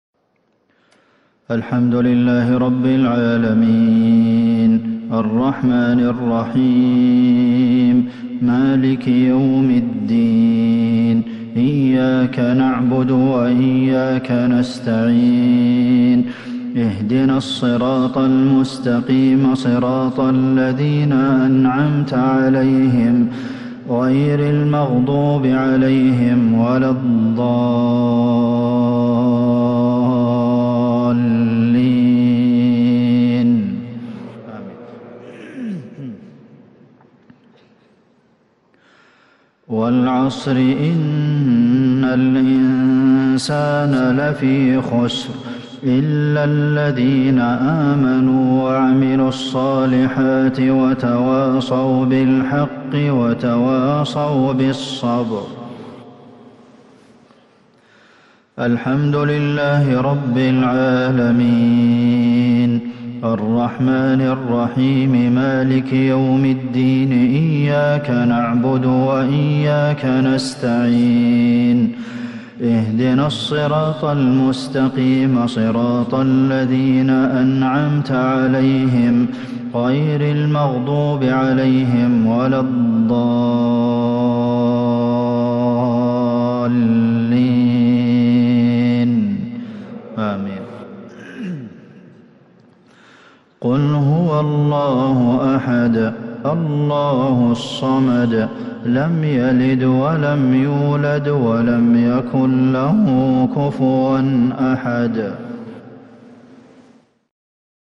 مغرب السبت 1-4-1443هـ سورتي العصر والإخلاص | Maghrib prayer Surah Al-‘Asr and Al-Ikhlas 6/11/2021 > 1443 🕌 > الفروض - تلاوات الحرمين